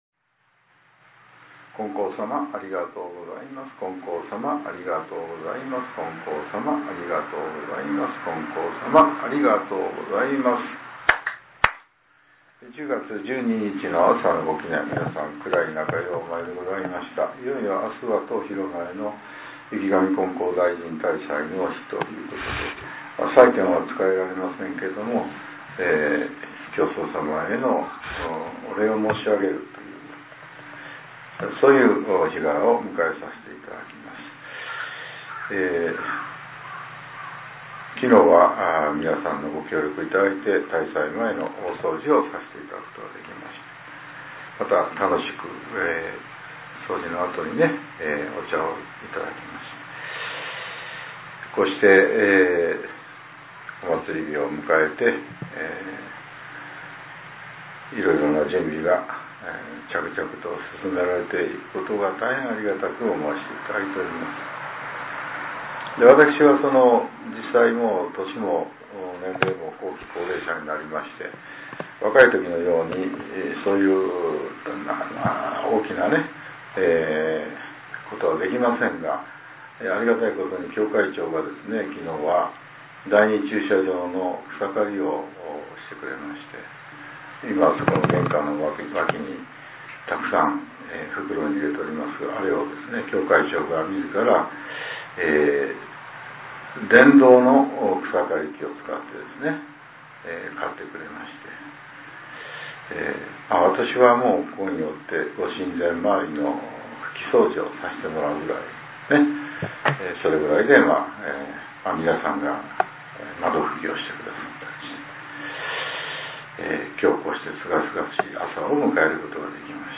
令和７年１０月１２日（朝）のお話が、音声ブログとして更新させれています。